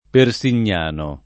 Persignano [ per S in’n’ # no ] top. (Tosc.)